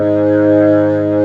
55o-org03-G#1.aif